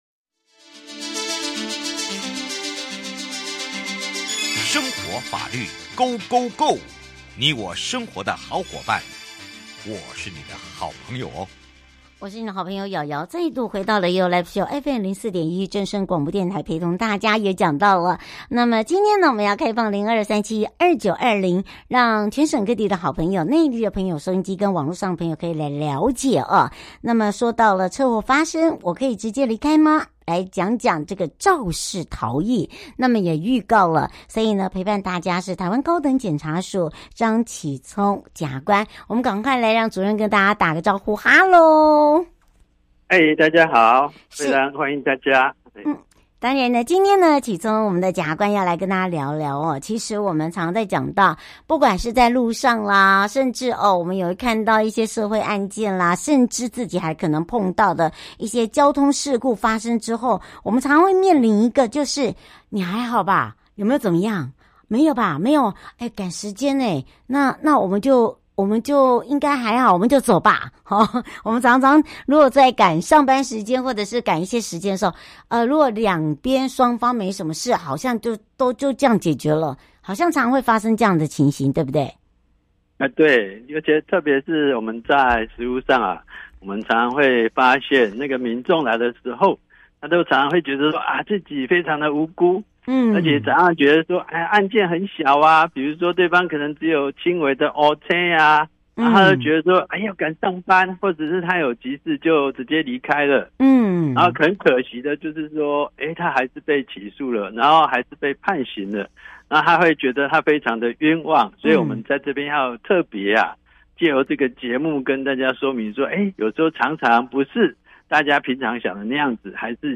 受訪者： 臺灣高等檢察署張啟聰檢察官 節目內容： 主題：車禍發生了，我可以直接離開嗎？